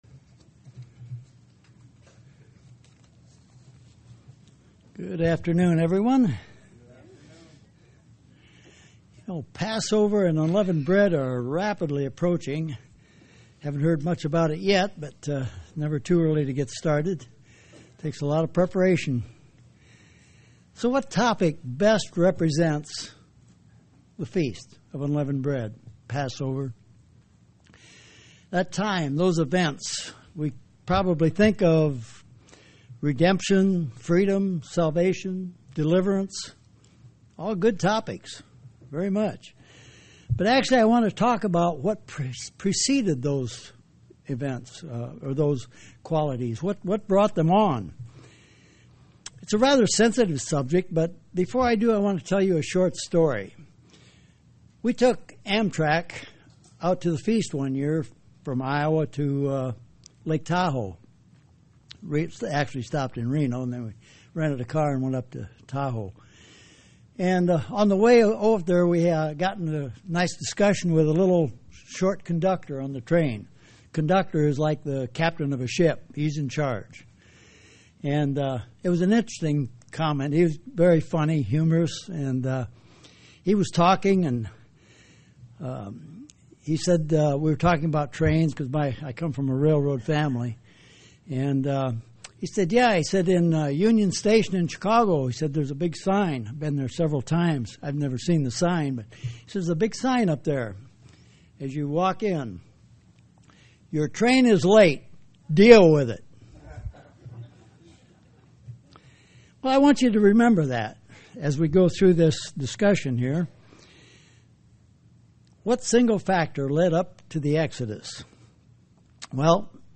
UCG Sermon Studying the bible?
Given in Tampa, FL